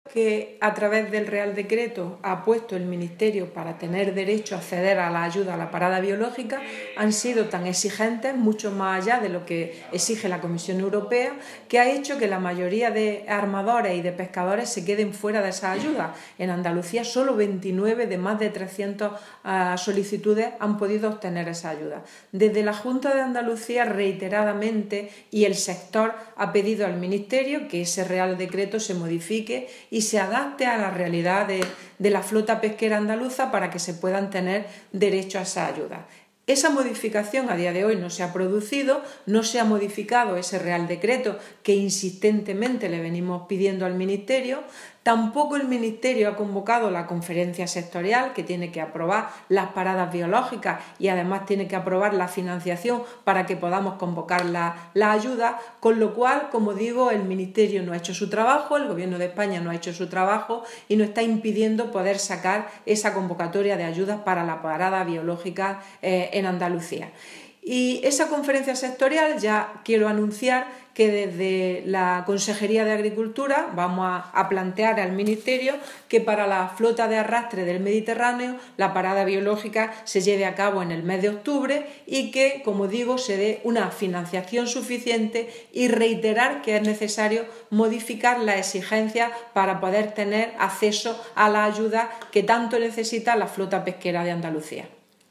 Declaraciones de Carmen Ortiz sobre criterios para acceder a las ayudas por parada biológica